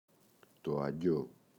αγγειό, το [a’ŋɟo] – ΔΠΗ